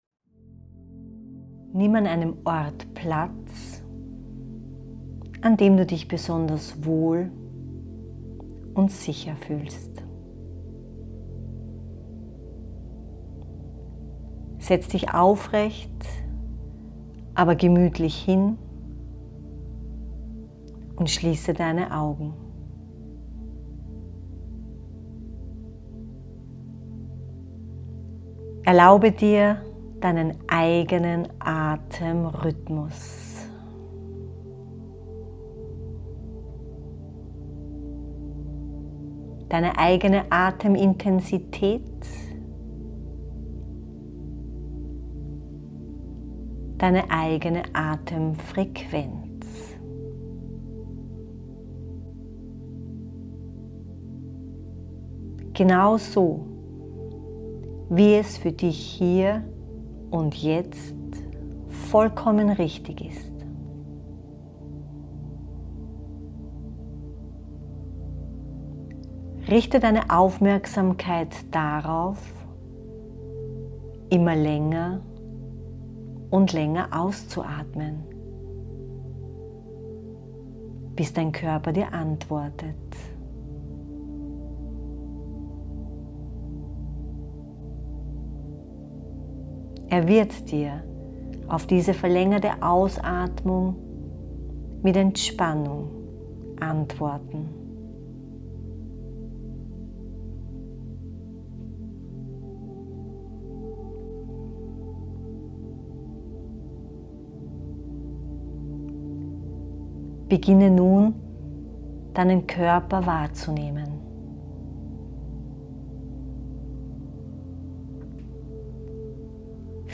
Die My true self-Meditation führt dich über die Grenzen der Sinneswahrnehmung hinaus – in den Raum reinen Bewusstseins.